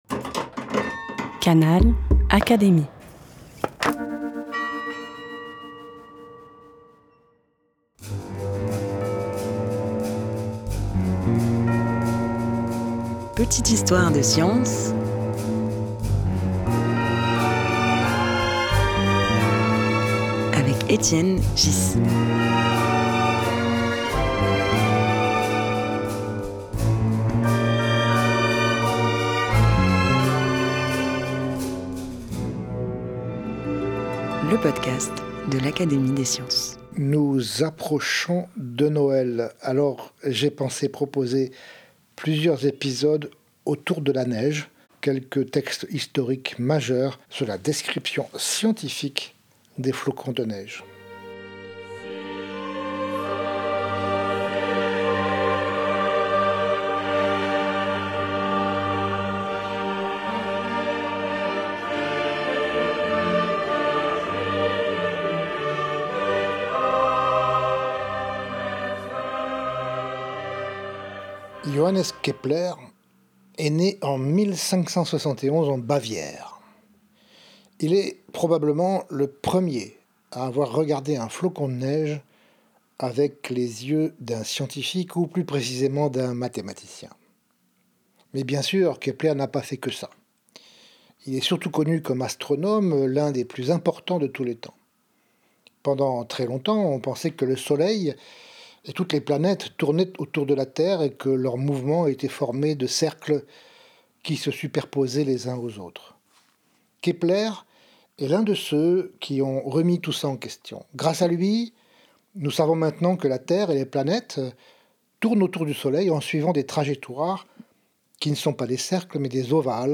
À travers la lecture de son texte Cadeau du nouvel an ou du flocon de neige hexagonal , Étienne Ghys, Secrétaire perpétuel de l’Académie des sciences, nous replonge au moment où l’émerveillement de Kepler ouvre la voie à une véritable réflexion scientifique.